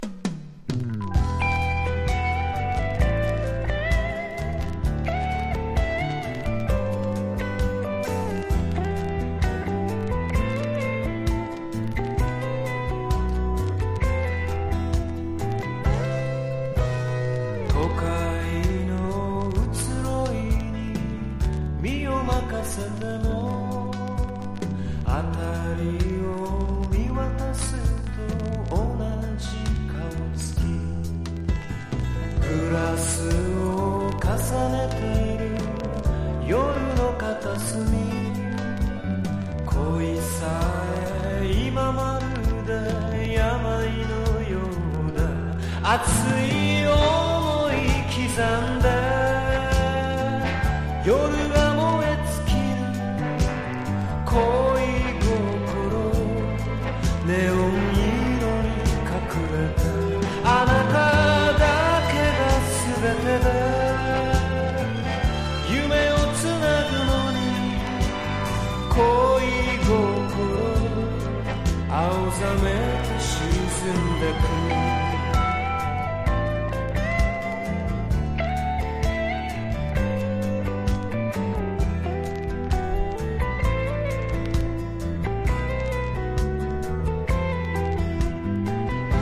# SSW / FOLK# CITY POP / AOR